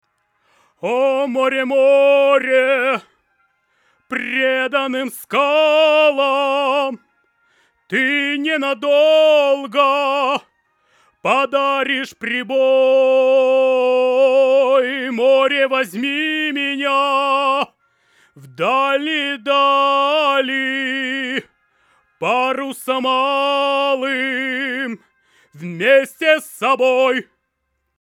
Блинн, забыл отключить переворот фазы одного канала. (В него обычно подключён самодельный ламповый бестрансформаторный микрофон с линейным выходом).